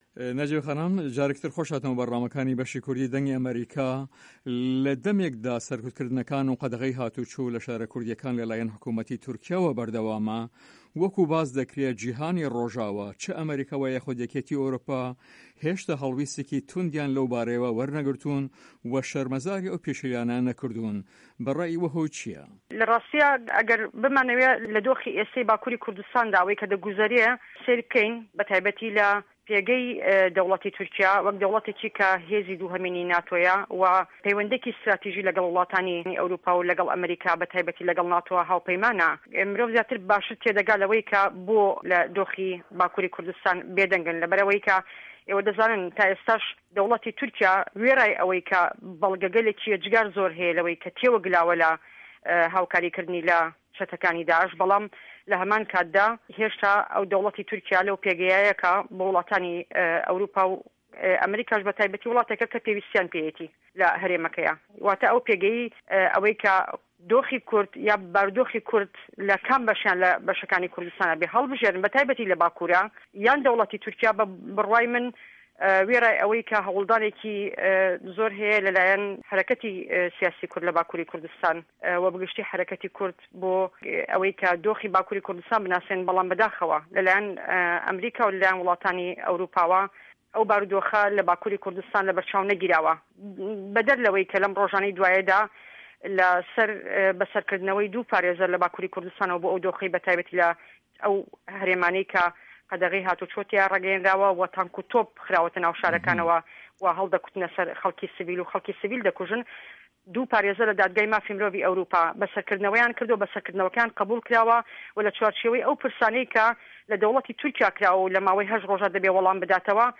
لە هەڤپەیڤینێکدا لەگەڵ بەشی کوردی دەنگی ئەمەریکا